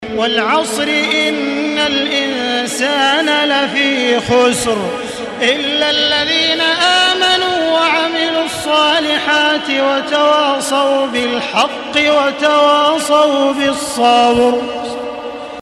Surah Al-Asr MP3 in the Voice of Makkah Taraweeh 1435 in Hafs Narration
Murattal Hafs An Asim